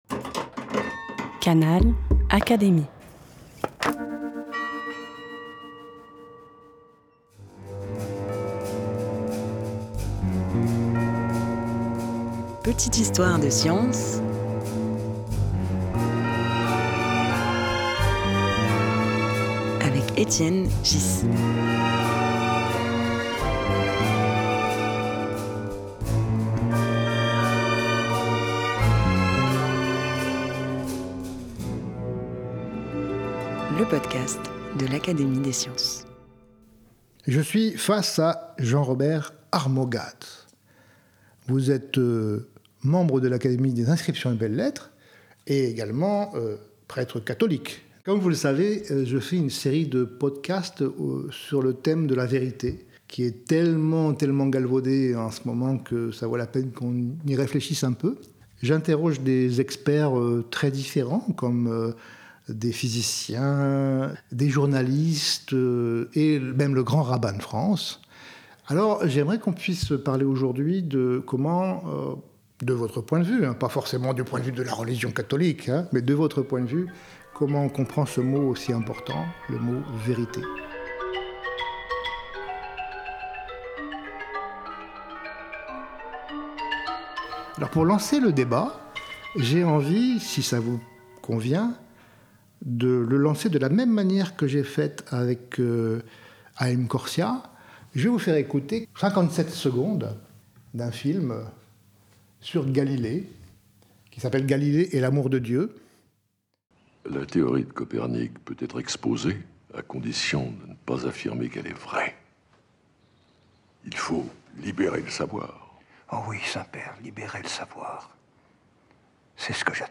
Cette semaine, Étienne Ghys reçoit Jean-Robert Armogathe, théologien membre de l’Académie des inscriptions et belles-lettres pour interroger la vérité dans le discours religieux.
Un podcast animé par Étienne Ghys, proposé par l'Académie des sciences.